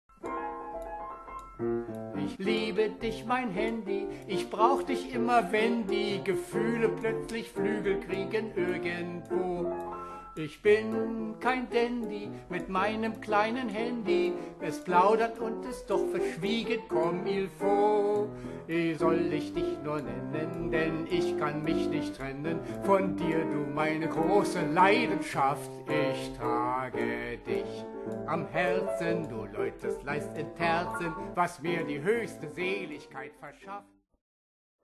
Musical-Komödie für fünf Schauspieler
Klavier und Gesang